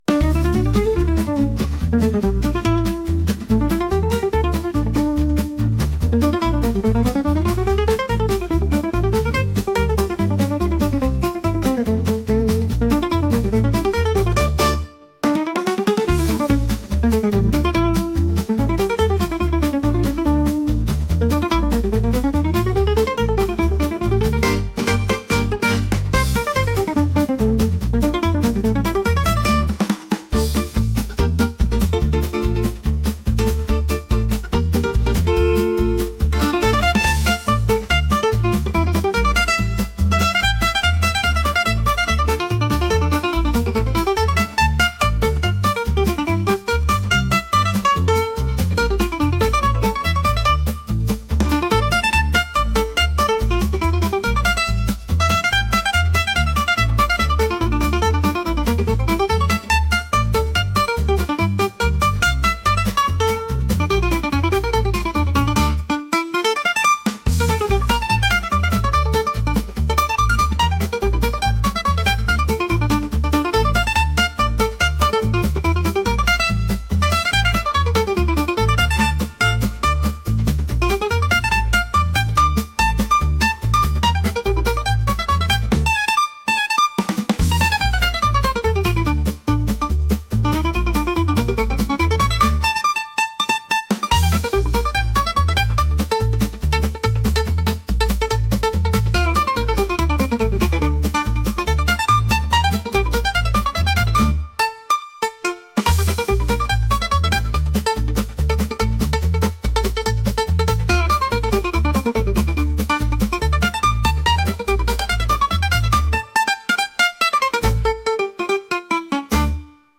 jazz | energetic | lively